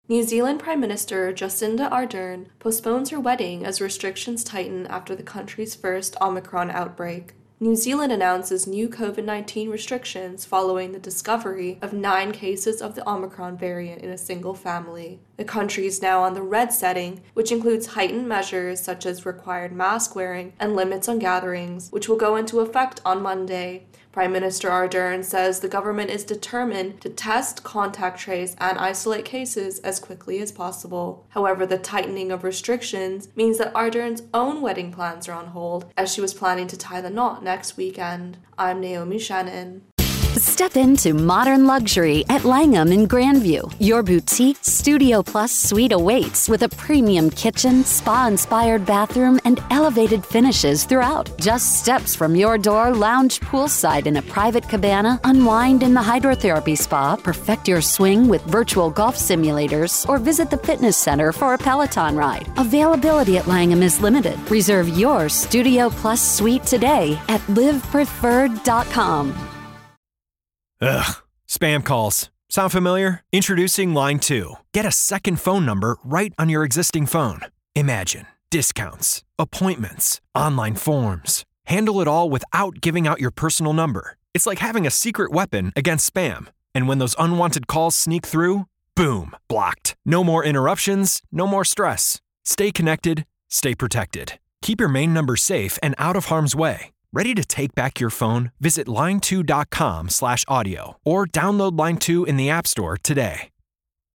New Zealand Virus Outbreak intro & voicer